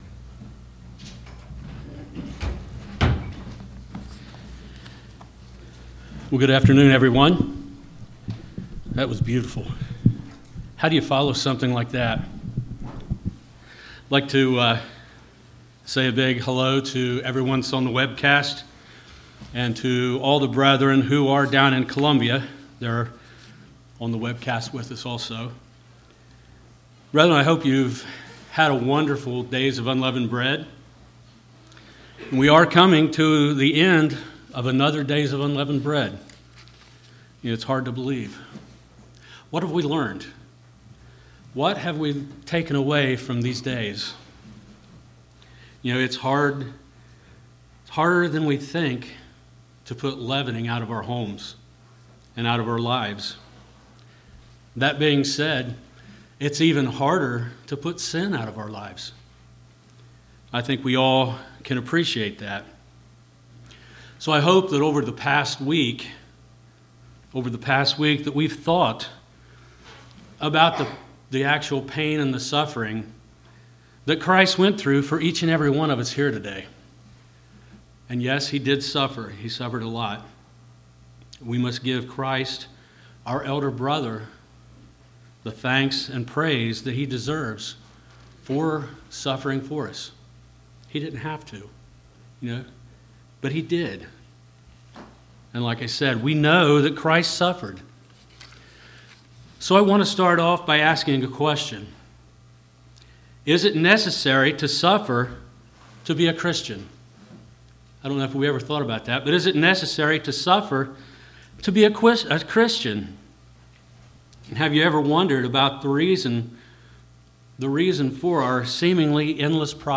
Sermons
Given in Portsmouth, OH Paintsville, KY